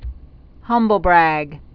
(hŭmbəl-brăg)